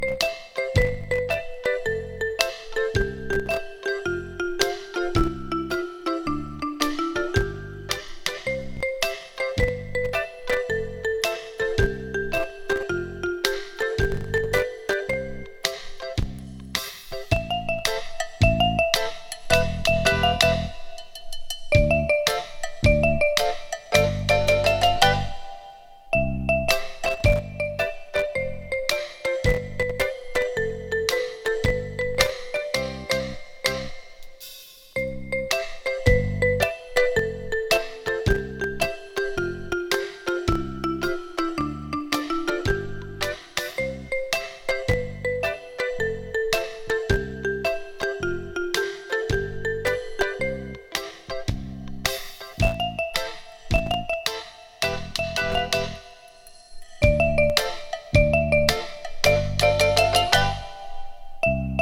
サントラ
コミカルなほんわかインスト